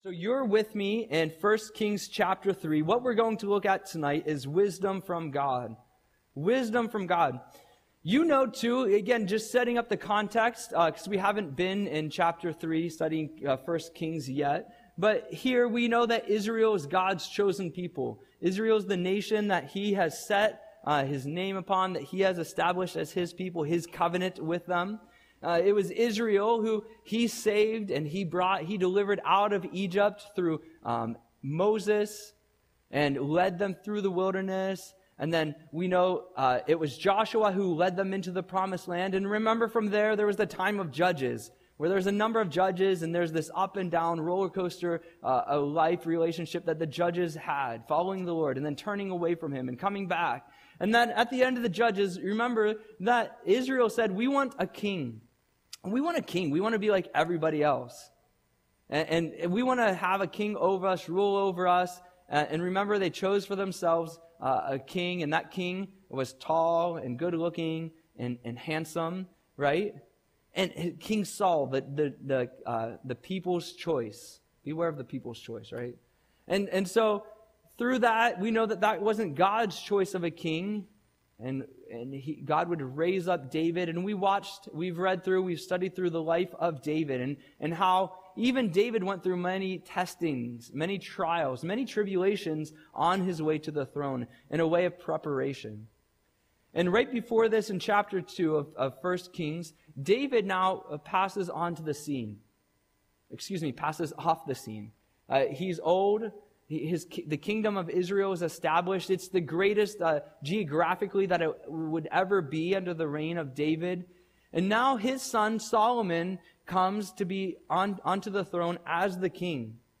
Audio Sermon - March 12, 2025